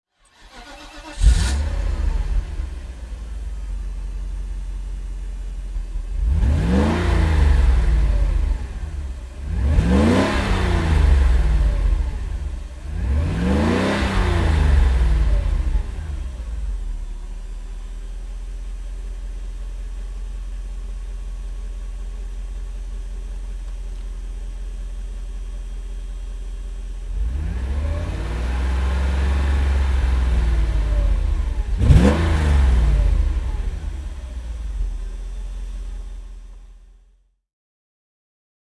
Mercedes-Benz 500 E (1992) - Starten und Leerlauf